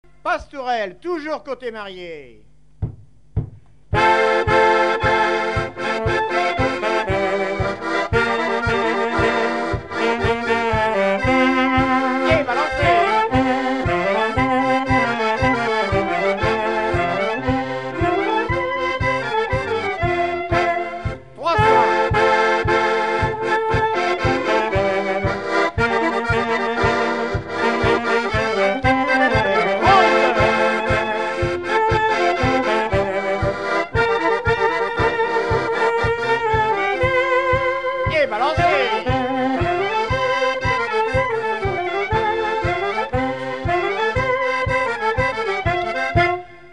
gestuel : danse
Pièce musicale inédite